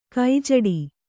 ♪ kai jaḍi